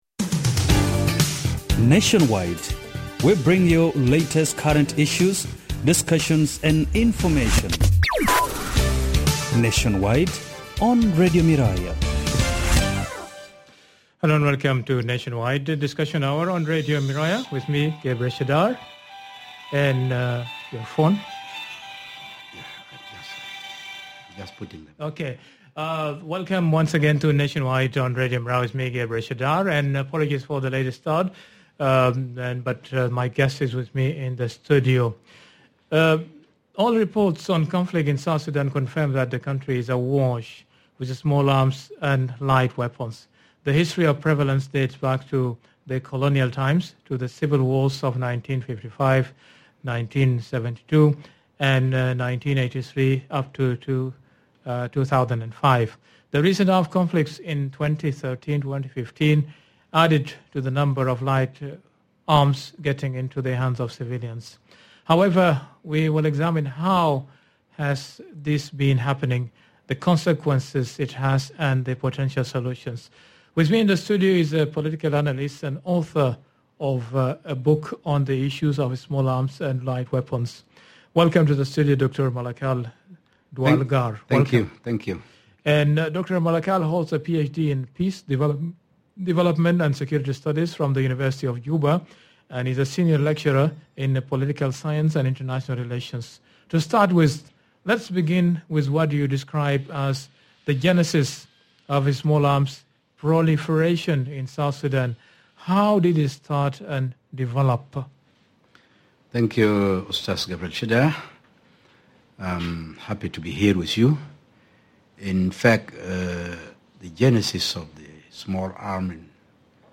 In this episode, we examine how the proliferation of arms began, its impact on society, and possible solutions. Joining me in the studio